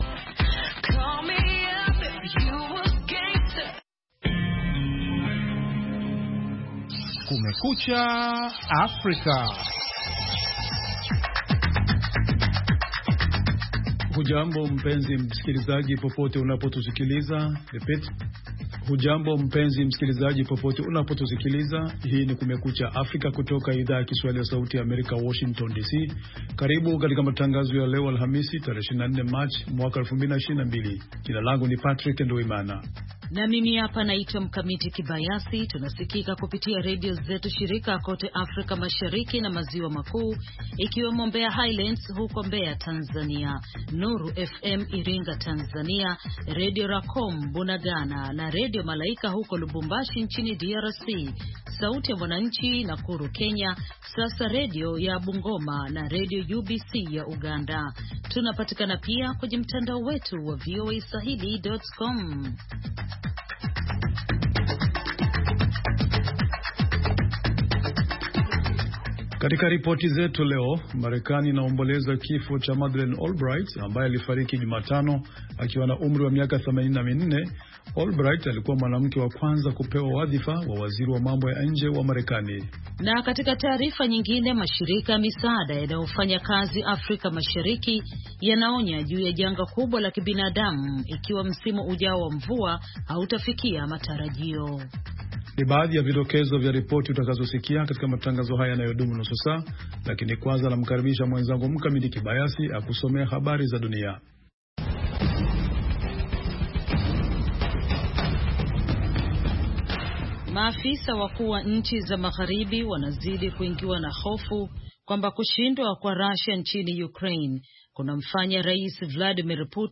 Matangazo ya nusu saa kuhusu habari za mapema asubuhi pamoja na habari za michezo.